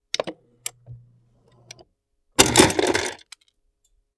Part_Assembly_18.wav